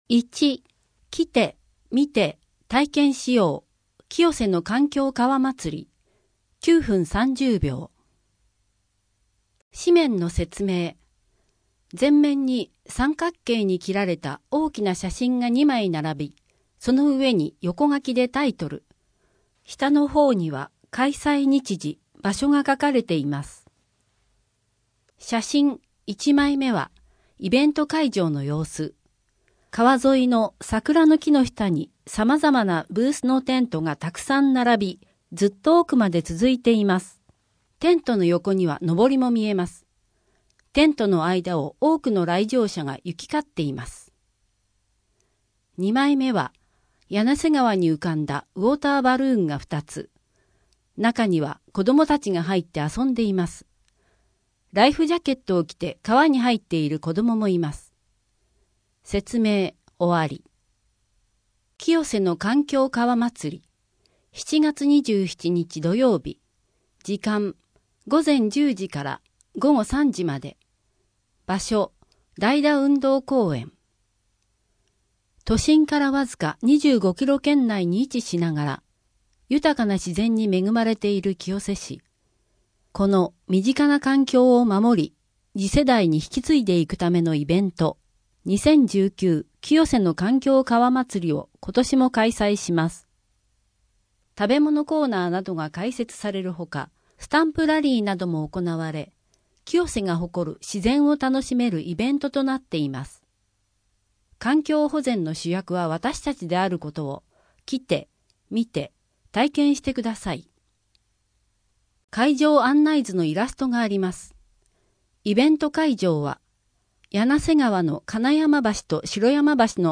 東京2020オリンピック 聖火ランナー募集 7月1日から市役所敷地内が禁煙に 各館のイベント情報・お知らせ 郷土博物館からのお知らせ 図書館からのお知らせ 児童館からのお知らせ 7・8月の子育て関連事業 今月の健康づくり 7月の休日診療 声の広報 声の広報は清瀬市公共刊行物音訳機関が制作しています。